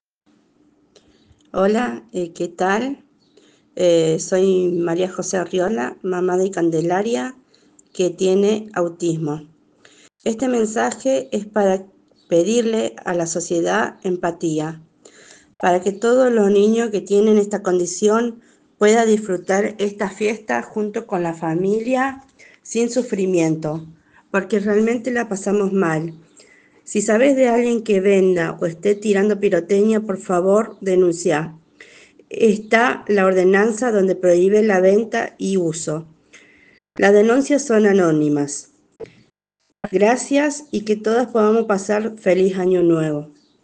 Escucha los audios de madres ceresinas de chicos con autismo….